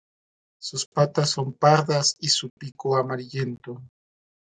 Pronounced as (IPA) /ˈpiko/